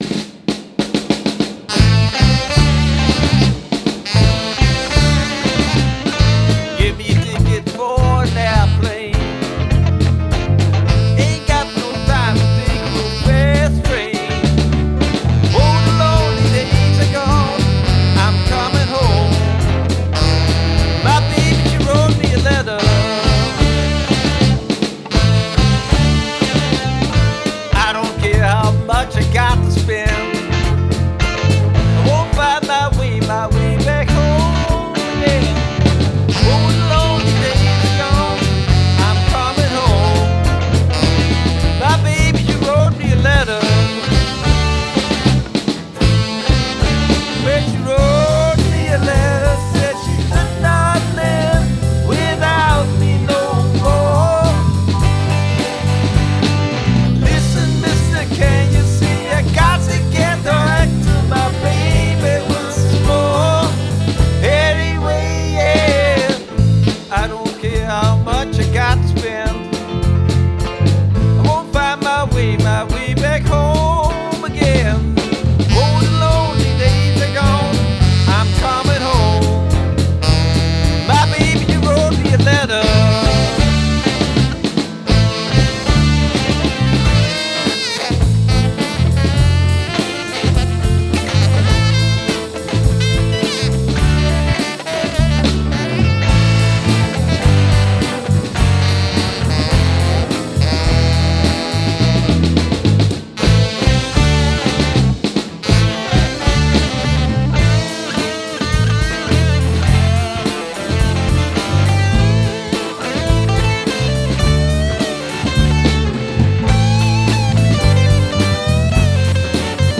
Studio-opnamen 2025